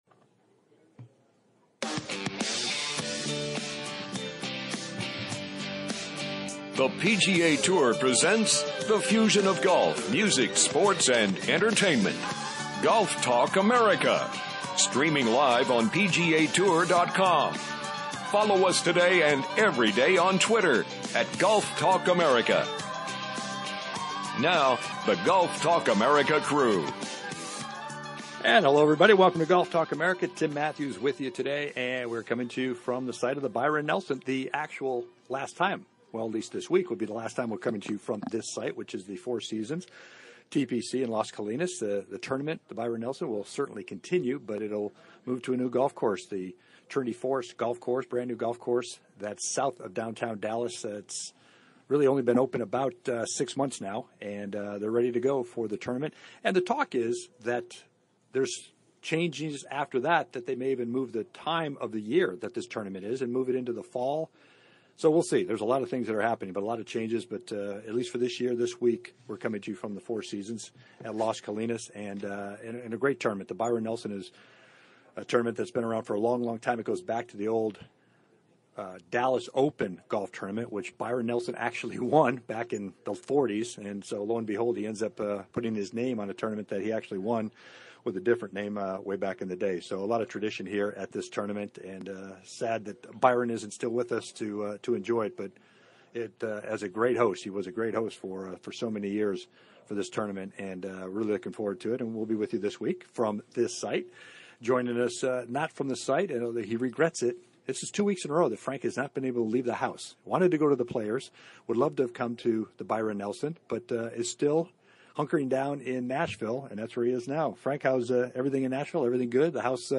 "LIVE" FROM THE AT&T BYRON NELSON